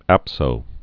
(ăpsō)